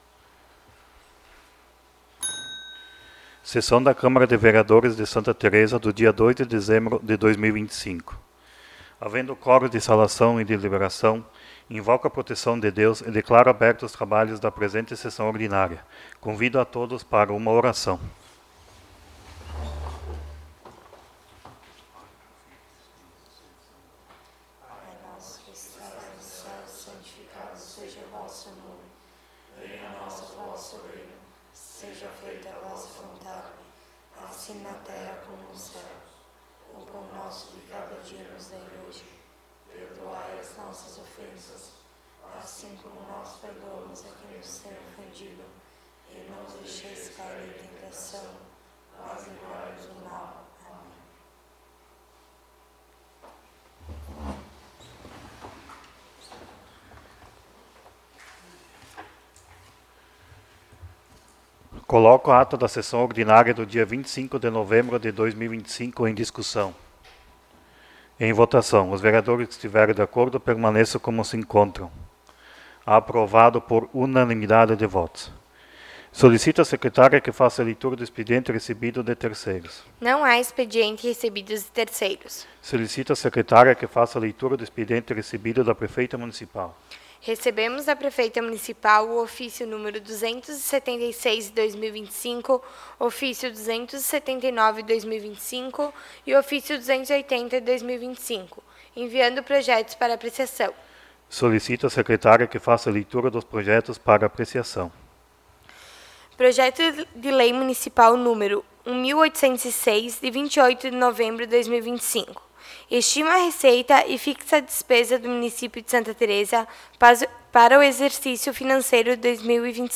21° Sessão Ordinária de 2025